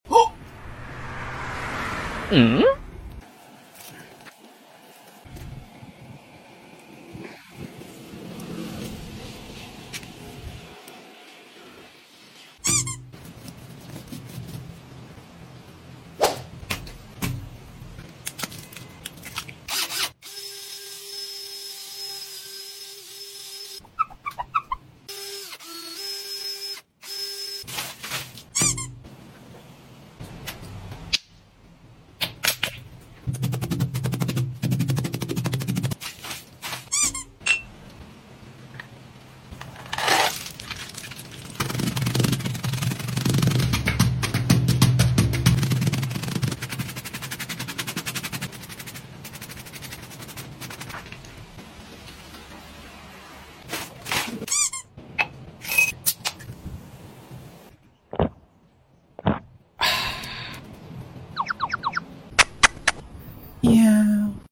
toy sound effects free download